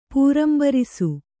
♪ pūrambarisu